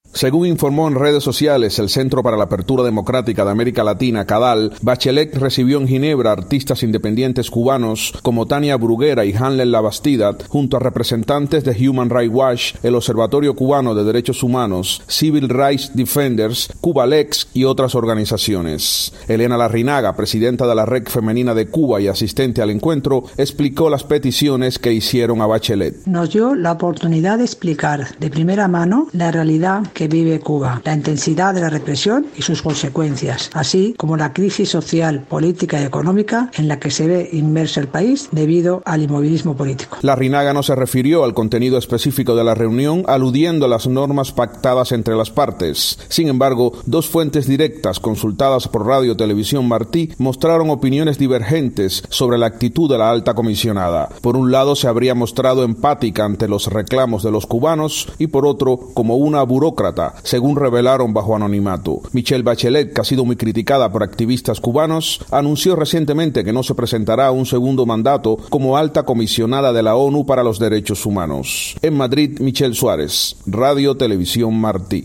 Reporte